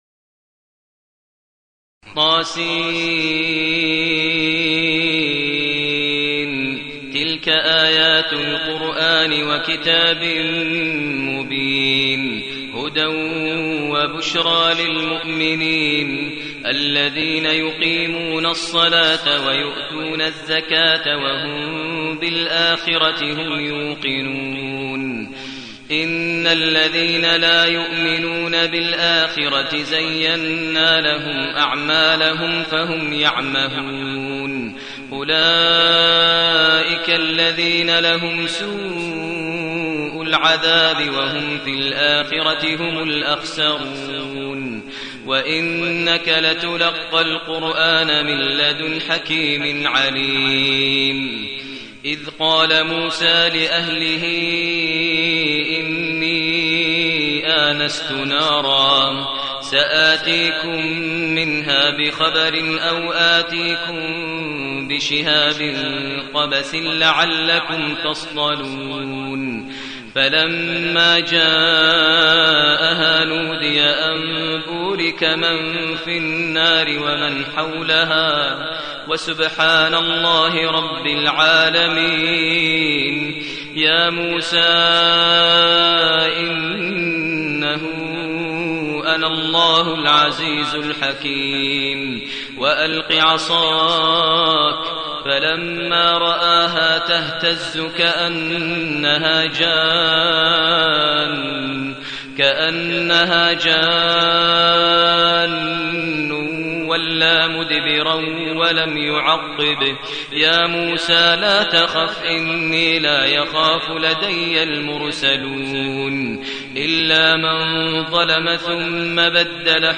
المكان: المسجد الحرام الشيخ: فضيلة الشيخ ماهر المعيقلي فضيلة الشيخ ماهر المعيقلي النمل